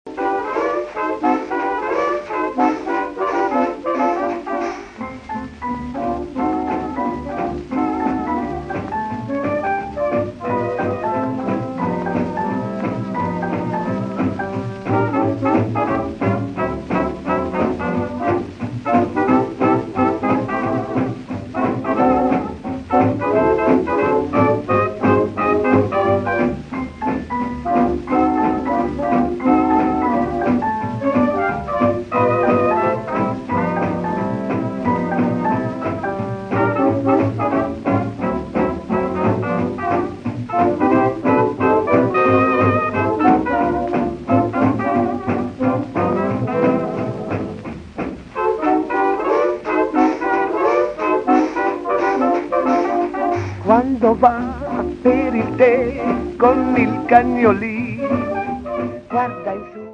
voce
riversati su cd da 78 giri degli anni 40